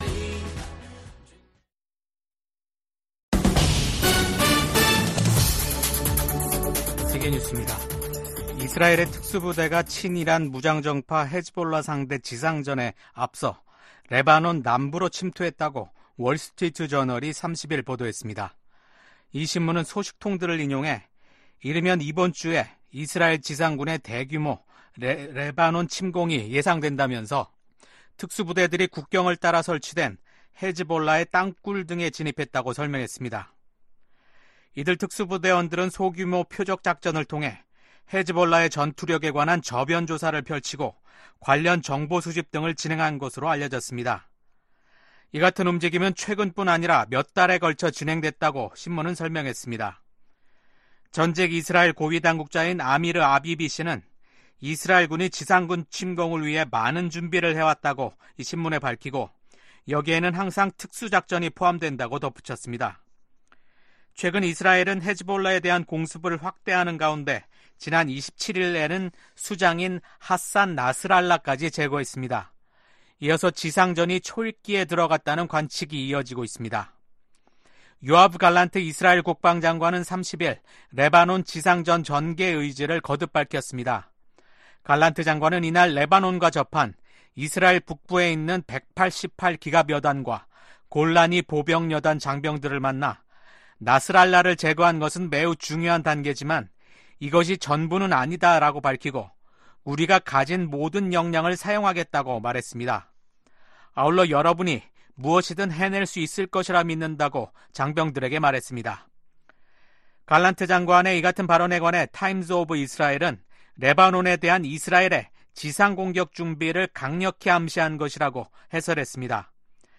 VOA 한국어 아침 뉴스 프로그램 '워싱턴 뉴스 광장' 2024년 10월 1일 방송입니다. 미국 국무장관이 북한을 압박해 러시아에 대한 무기 제공을 중단시켜야 한다고 강조했습니다. 미 국무부는 북한이 사실상 핵보유국이라는 국제원자력기구(IAEA) 수장의 발언과 관련해 한반도의 완전한 비핵화 목표를 다시 한 번 확인했습니다. 한국 정부는 북한 비핵화 목표를 부정한 러시아 외무장관의 발언에 대해 무책임하다고 비판했습니다.